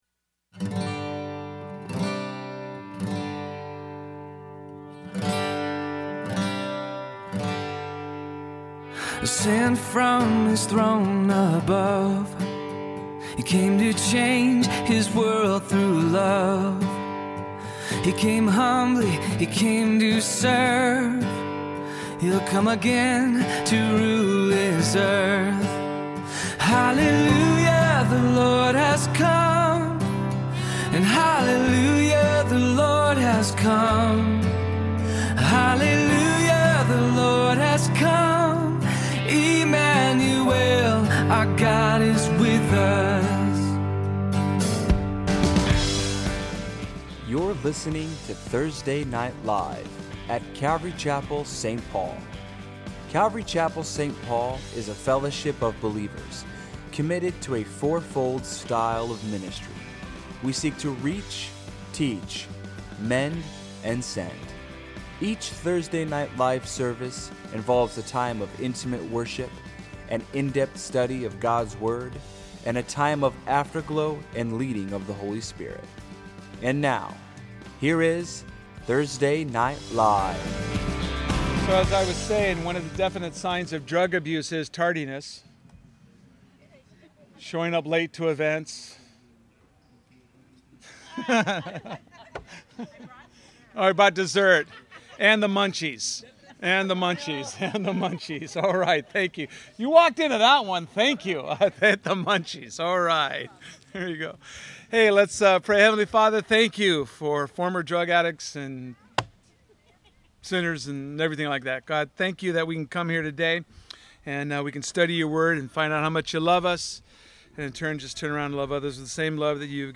A message from the series "Thursday Evening."